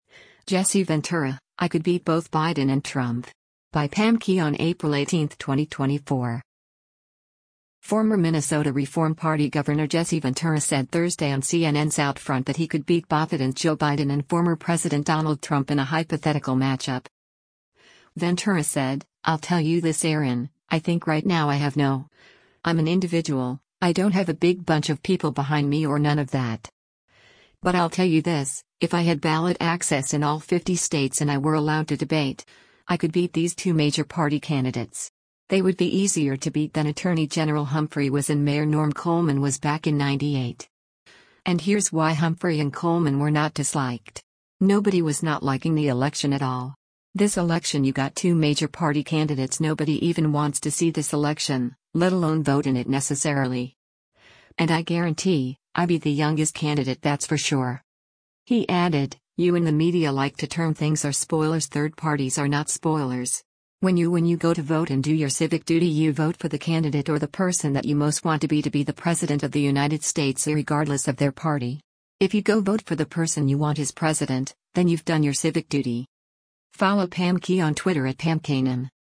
Former Minnesota Reform Party Gov. Jesse Ventura said Thursday on CNN’s “OutFront” that he could beat bothident Joe Biden and former President Donald Trump in a hypothetical match-up.